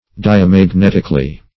Search Result for " diamagnetically" : The Collaborative International Dictionary of English v.0.48: Diamagnetically \Di`a*mag*net"ic*al*ly\, adv.
diamagnetically.mp3